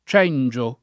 ©%nJo o